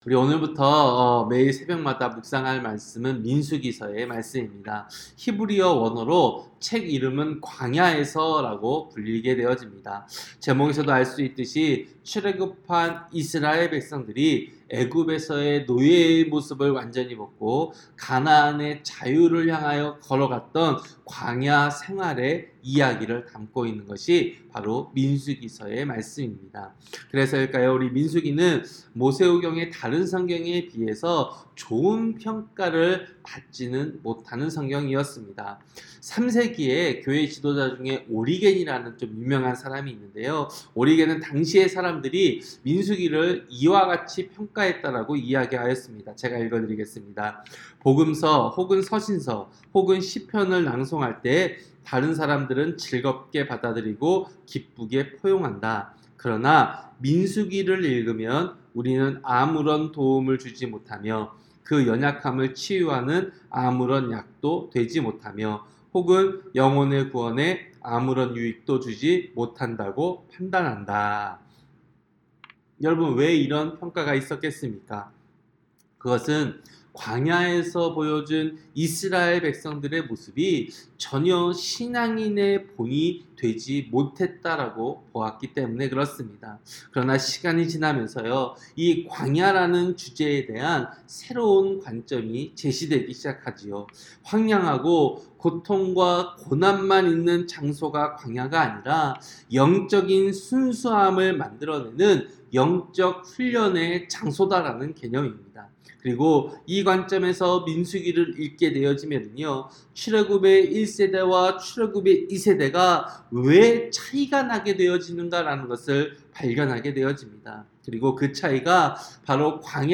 새벽설교-민수기 1장